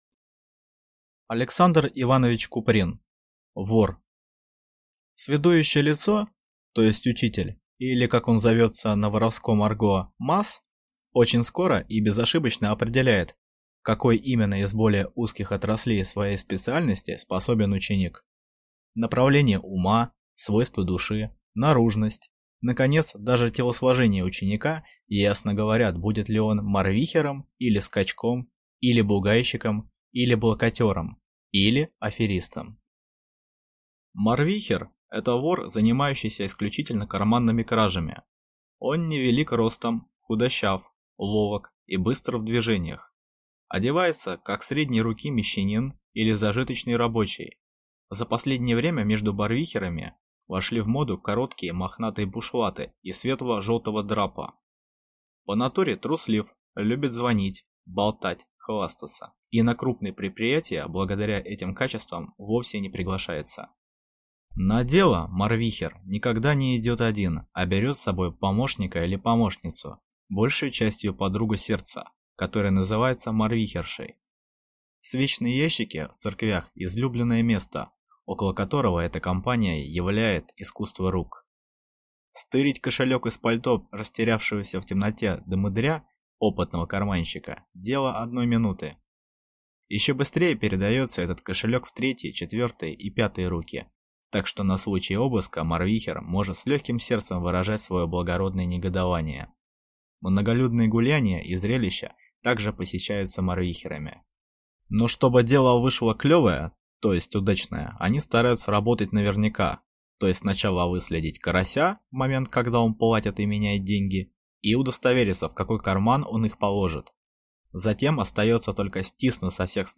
Aудиокнига Вор Автор Александр Куприн Читает аудиокнигу